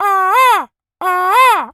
pgs/Assets/Audio/Animal_Impersonations/bird_vulture_squawk_01.wav at master
bird_vulture_squawk_01.wav